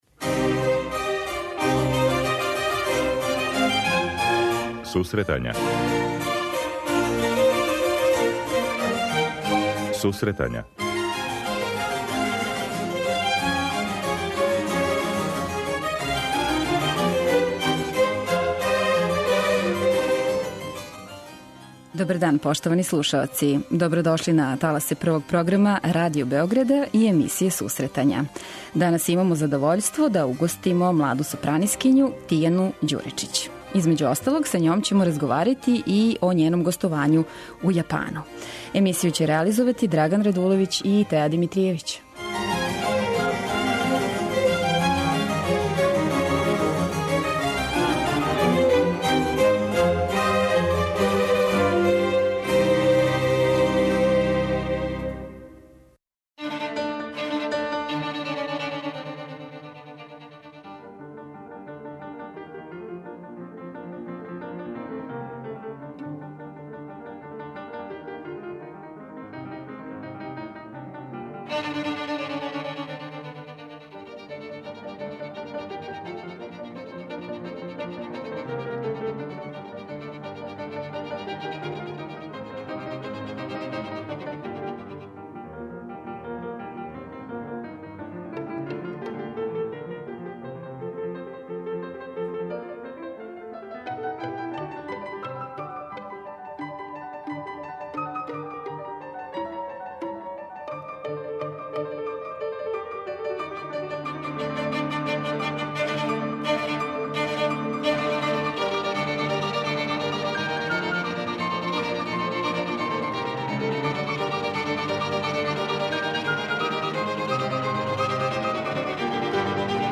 преузми : 26.03 MB Сусретања Autor: Музичка редакција Емисија за оне који воле уметничку музику.